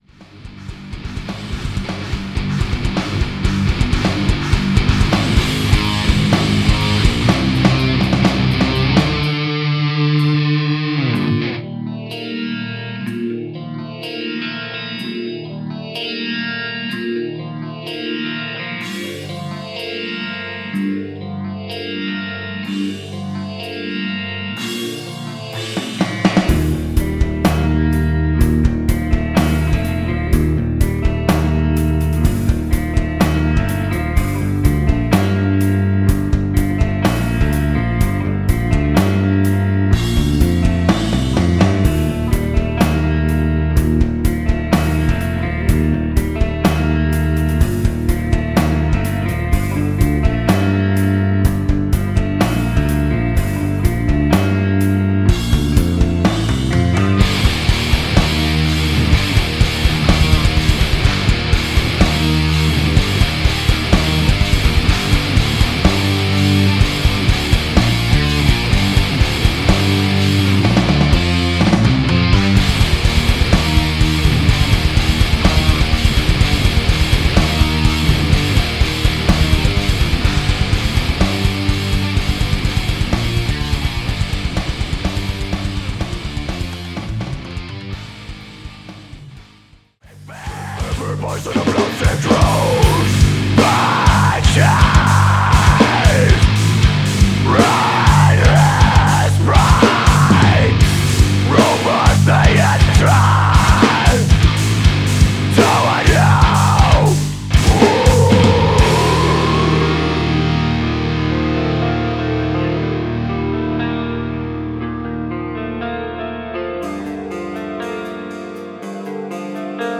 Eine uralte Demo die ich allein gemacht und später dann mit Band ausgearbeitet hab.